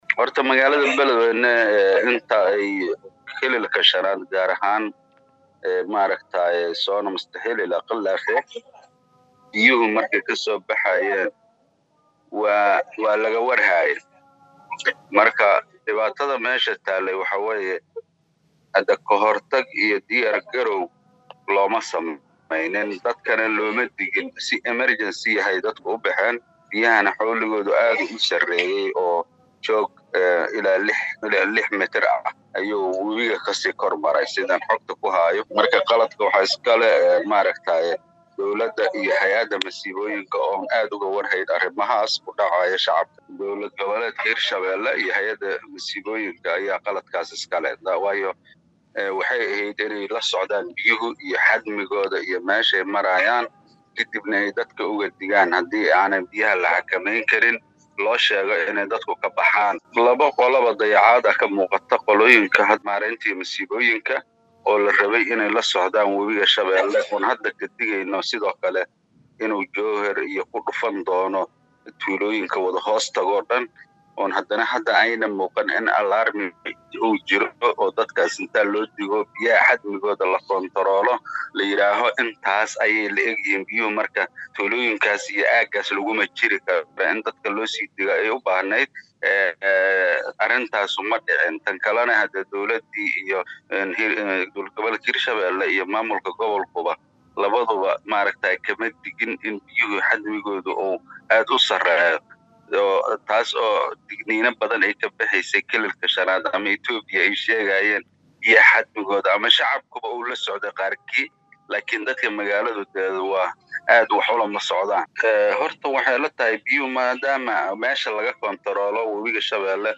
Xildhibaan hore ahna Siyaasi Xuseen Gaagaale ayaa ka hadlay FatahaadaWebiga shabeelle uu ka geystay Magaalada Baladweyne.
waraysi-Gagaale.mp3